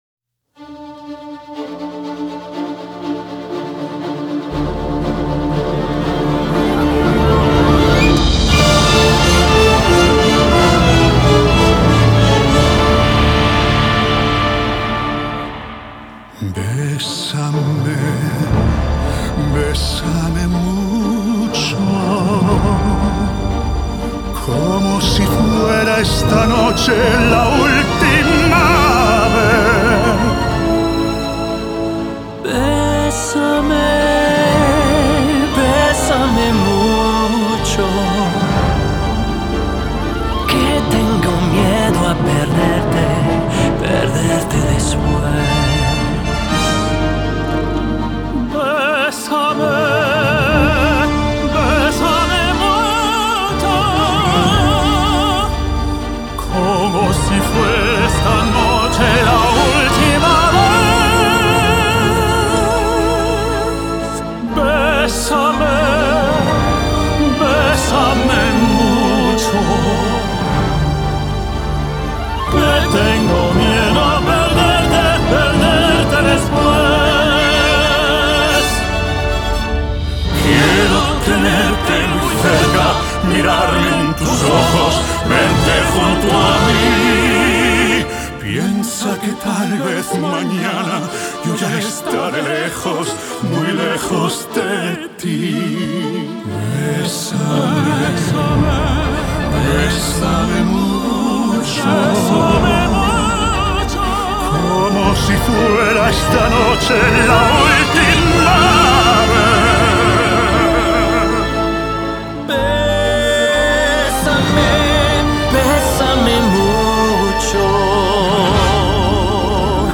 Genre: Classical Crossover